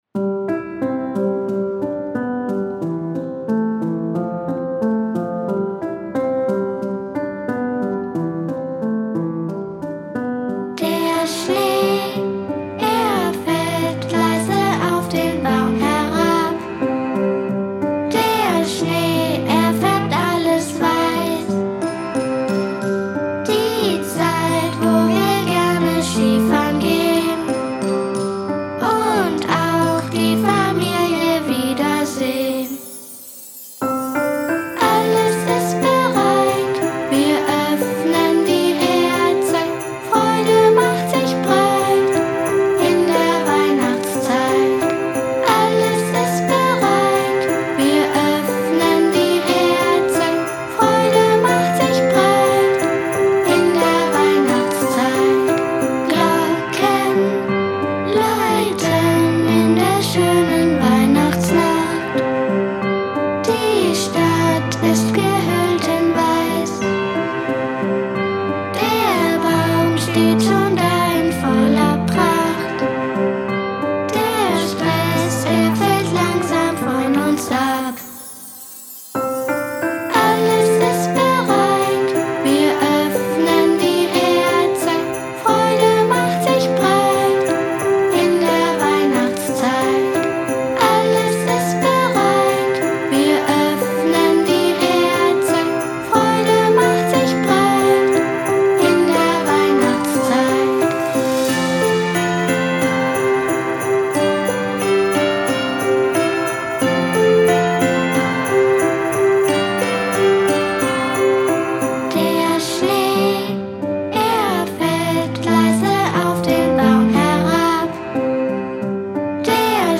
Weihnachtslied "Alles ist bereit"